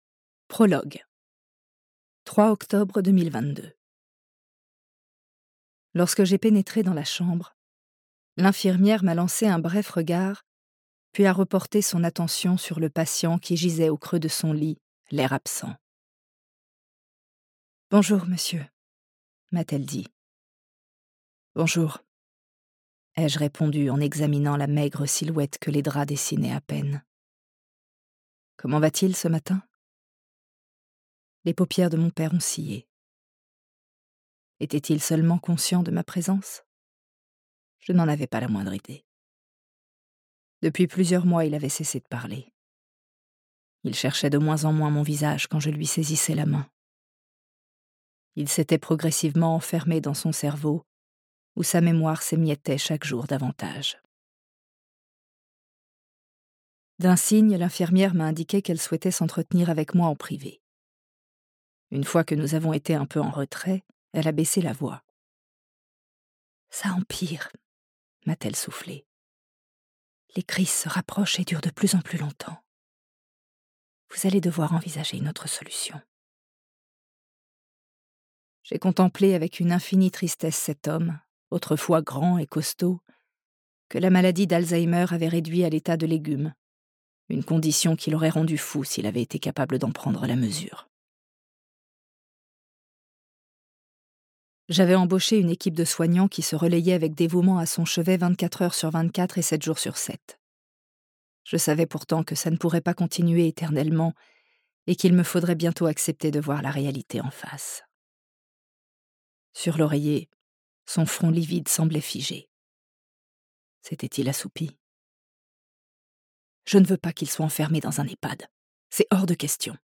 Porté par le timbre profond et éraillé